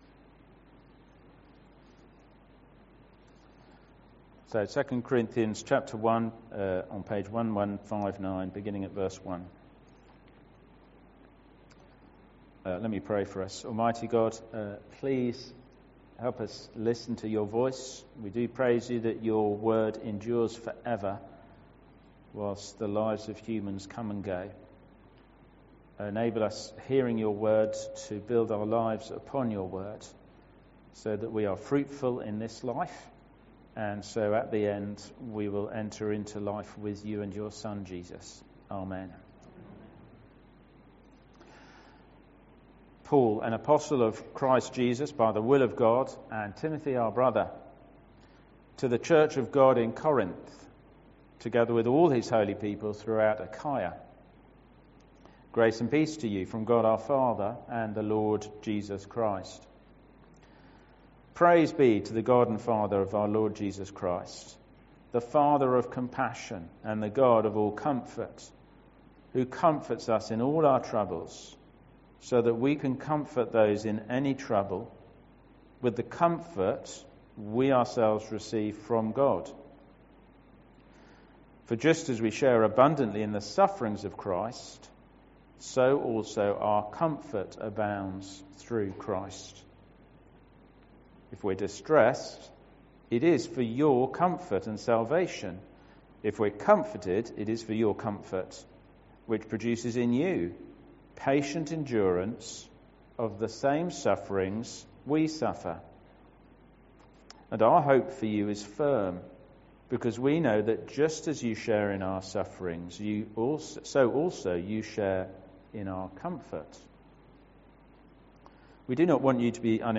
Sermons – Dagenham Parish Church